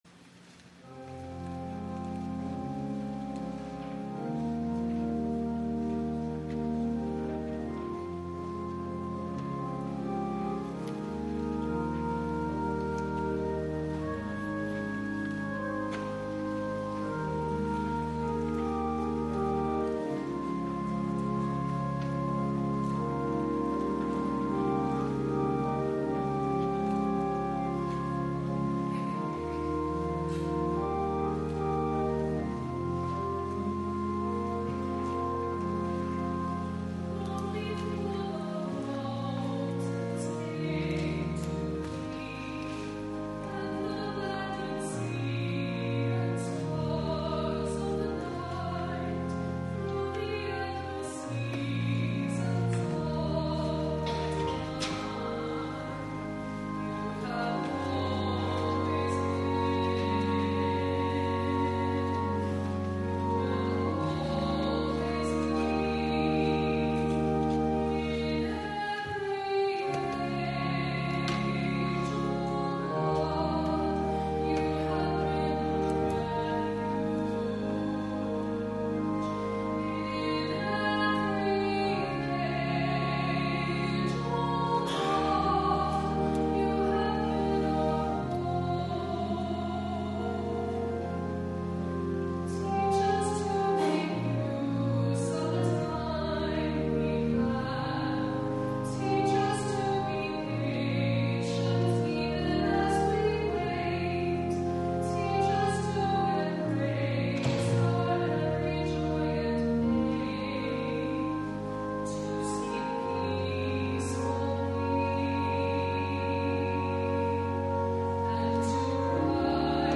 2008 media | Morningside Presbyterian Church
soloists